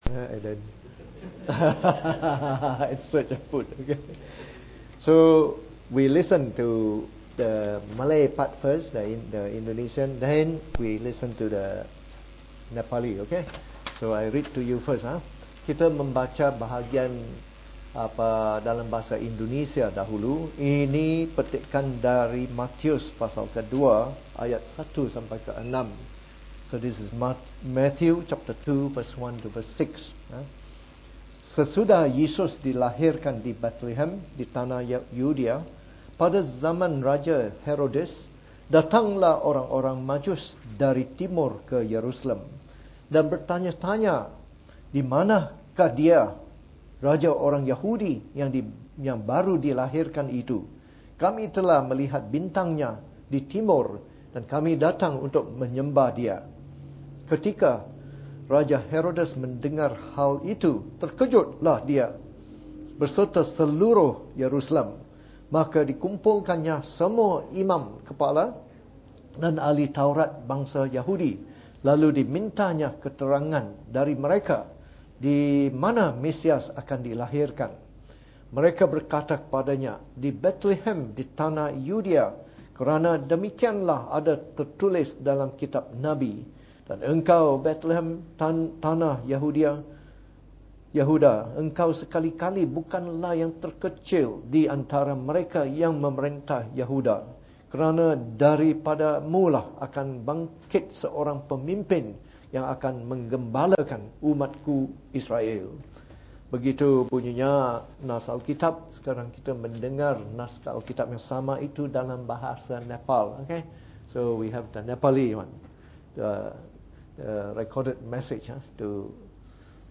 This message was preached on Christmas Day during our yearly Christmas service.